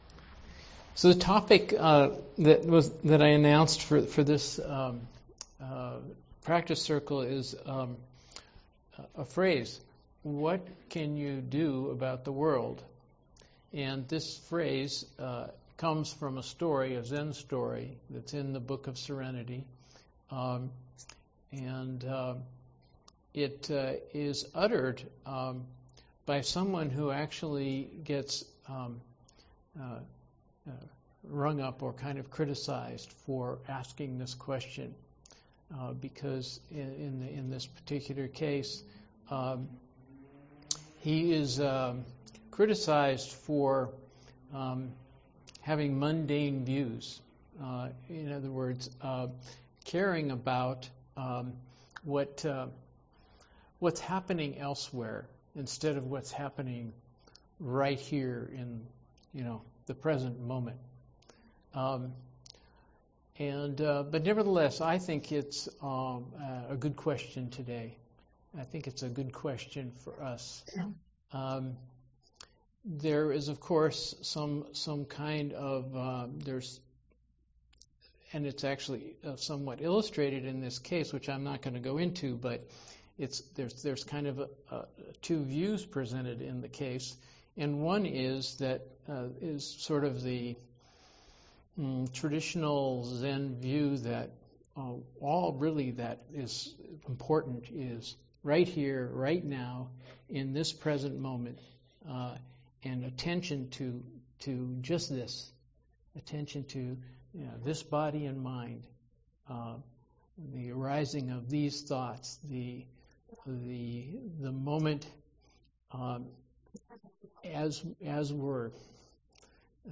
Dharma Talk given on Monday